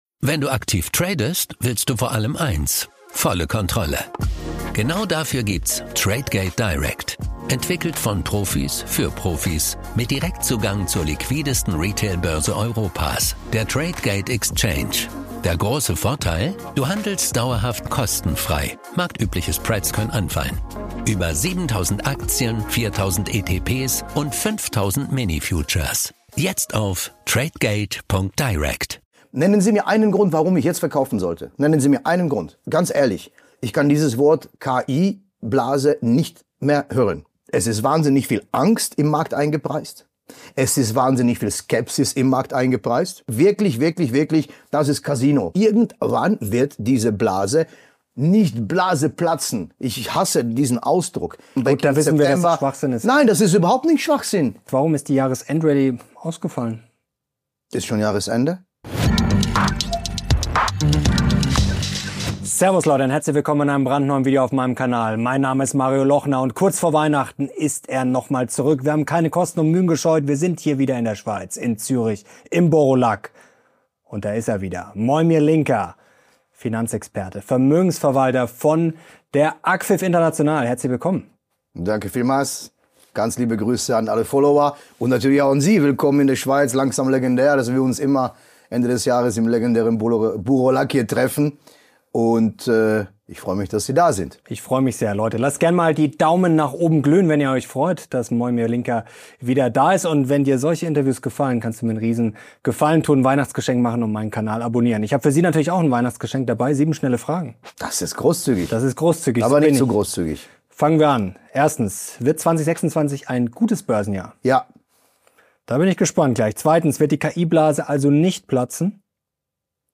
Wir haben den Finanzexperten in Zürich getroffen und ihm im Baur au Lac die wichtigsten Fragen gestellt, die jetzt für Anleger z...